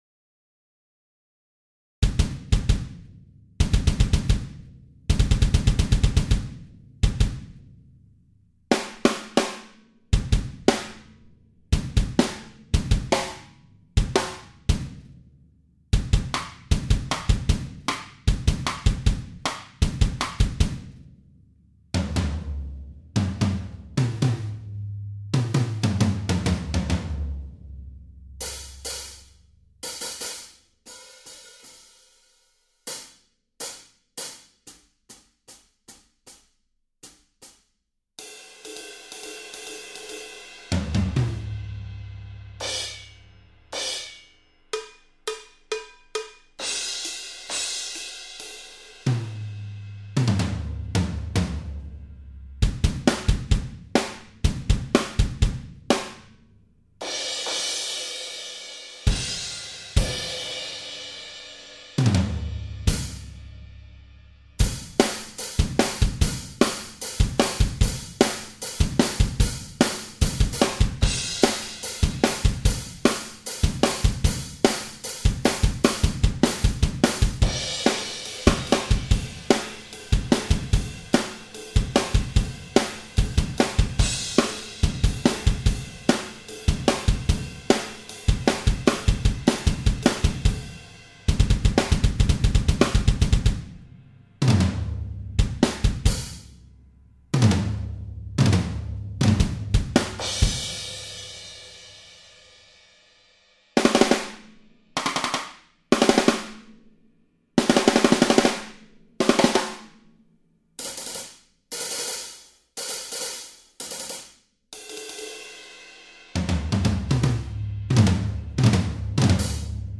- Aux doigts en midi sur mon synthé =